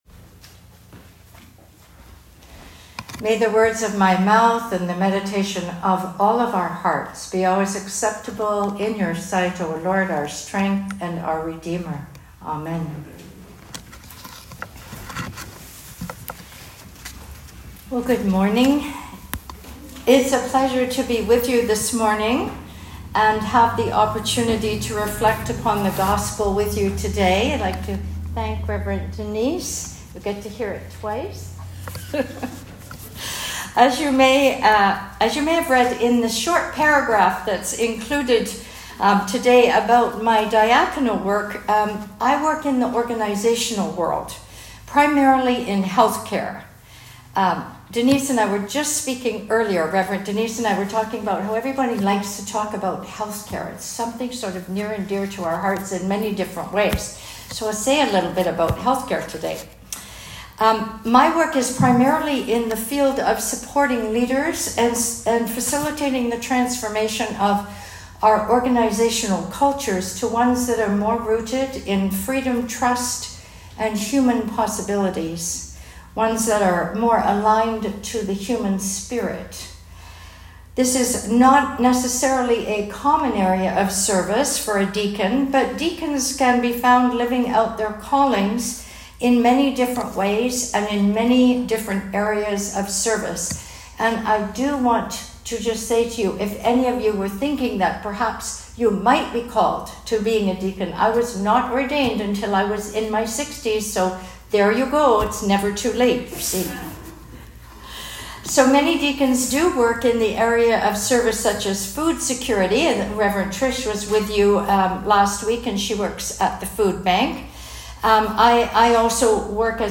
Sermon on Luke 12.32-40